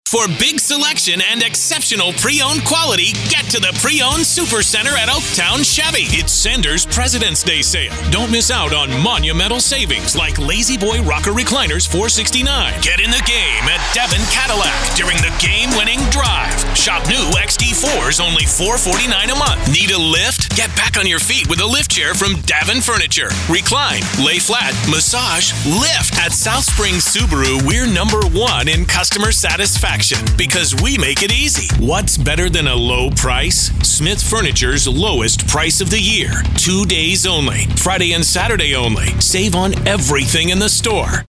Conversational, Honest, Caring, Sincere
Commercial Voiceover, TV and Radio, Auto Dealer